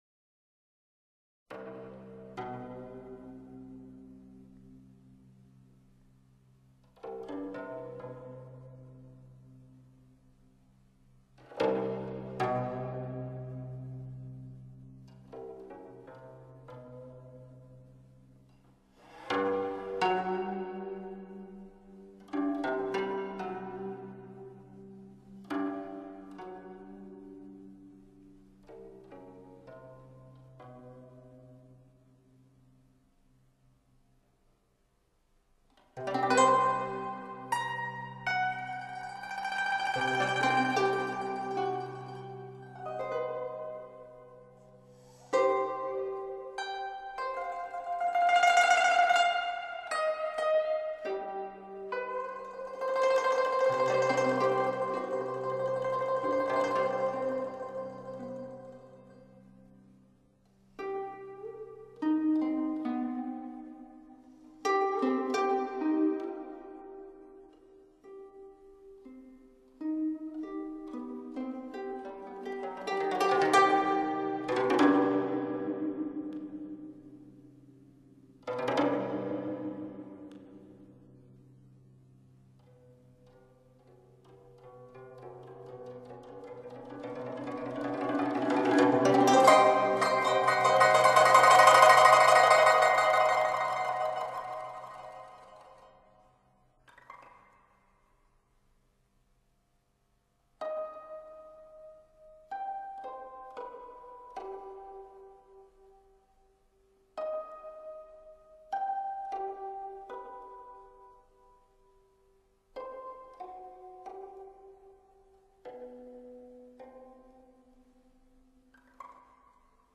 02 采茶曲（古筝独奏）